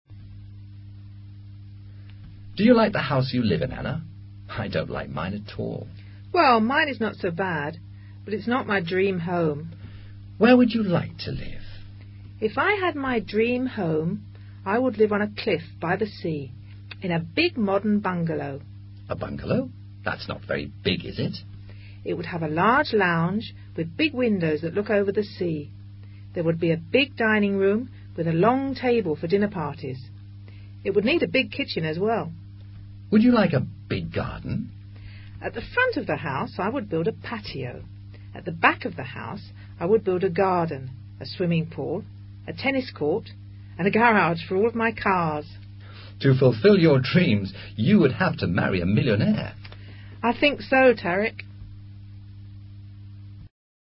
Diálogo en el cual un personaje le describe a otro su casa soñada.